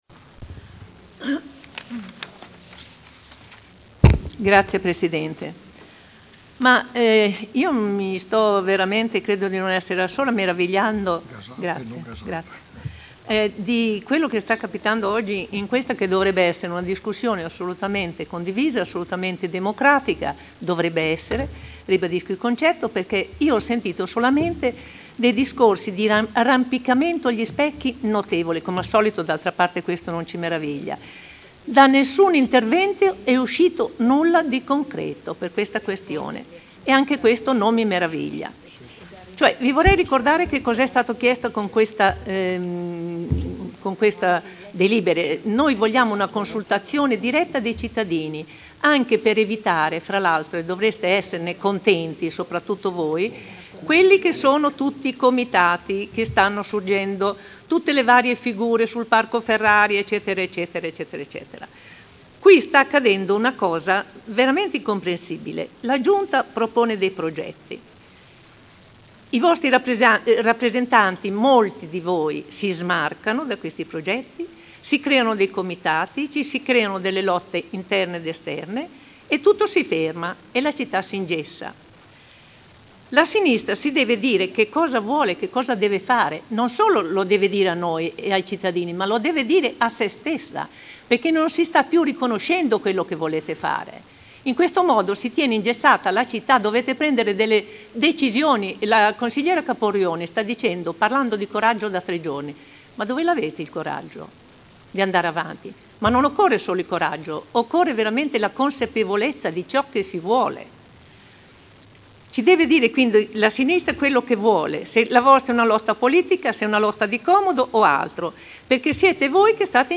Dibattito su delibera: Consultazione popolare ai sensi dell’art. 8 degli istituti di partecipazione del Comune di Modena sul progetto di ristrutturazione di Piazza Matteotti (Conferenza Capigruppo del 27 giugno 2011 e del 4 luglio 2011)